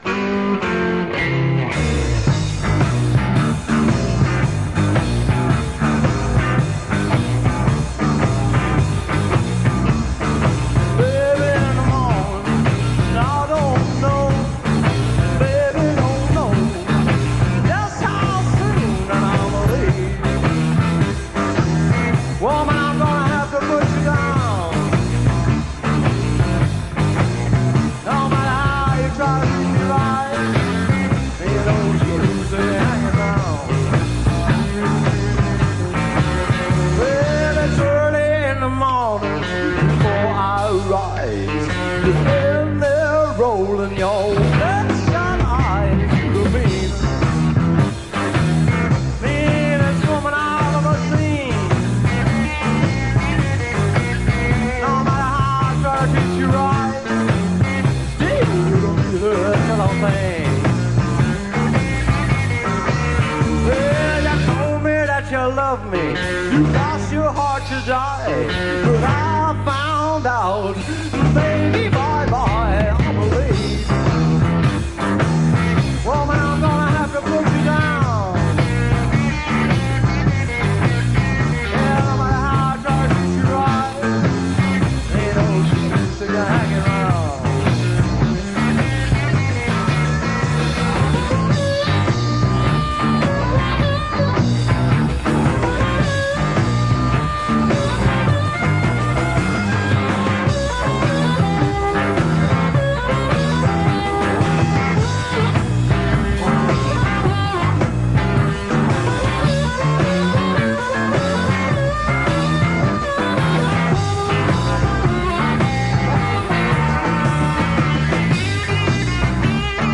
guitar and vocals
harmonica
bass
drums